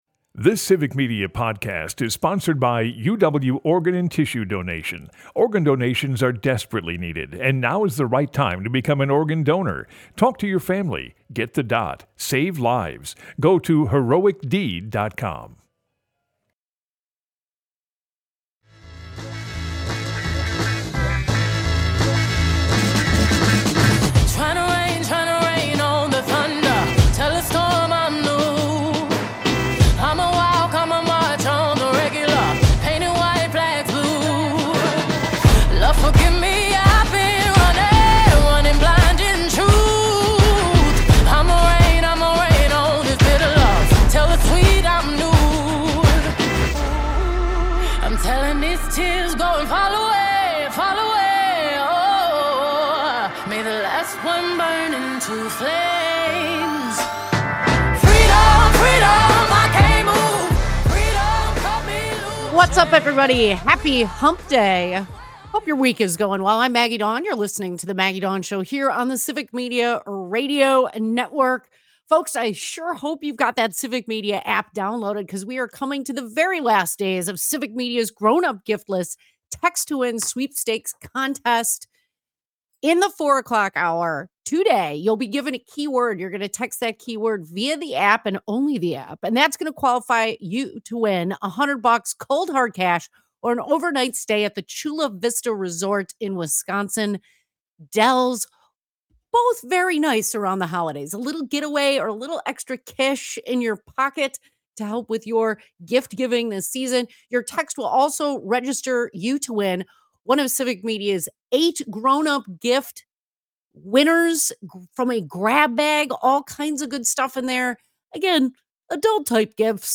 Broadcasts live, 2 - 4 p.m. across Wisconsin.